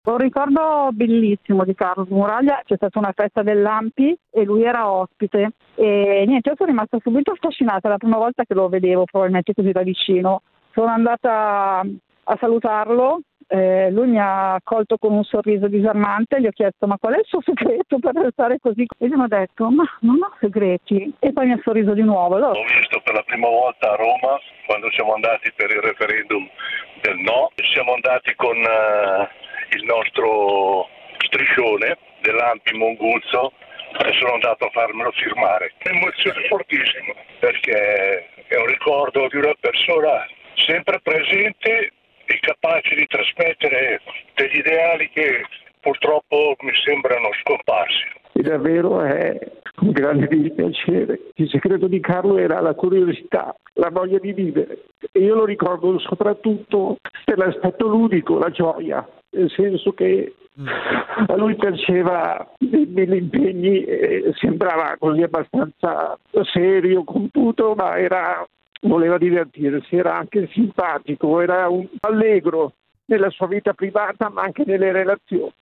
Oggi Radio popolare ha aperto i microfoni ad ascoltatori e ascoltatrici che hanno ricordato Smuraglia, sottolineando sia il suo impegno civile sia la sua umanità: